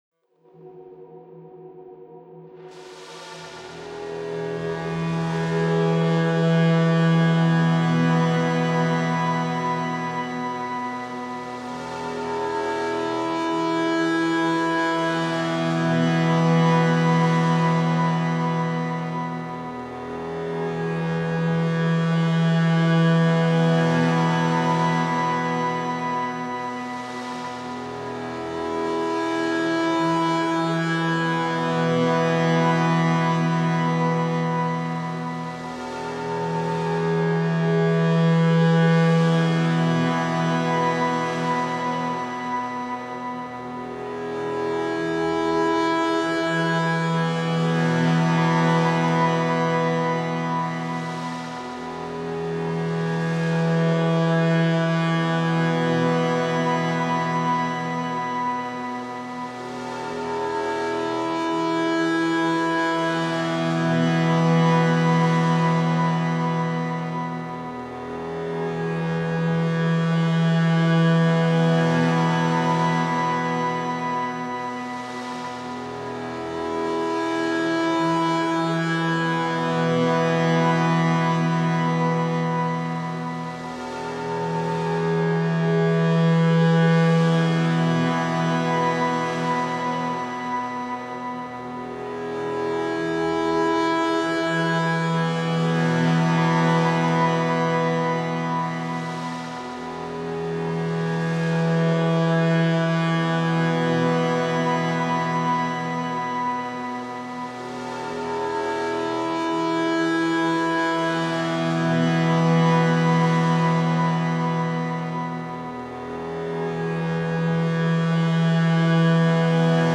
Strange uneasy ambient textures.